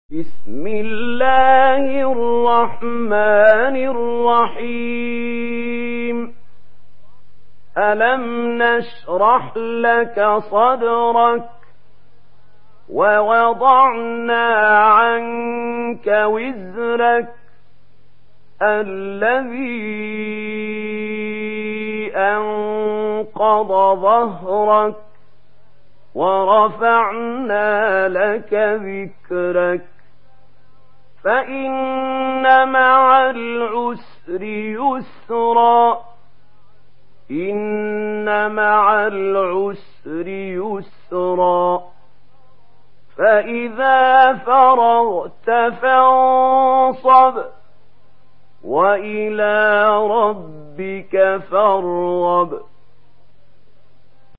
Surah الشرح MP3 by محمود خليل الحصري in ورش عن نافع narration.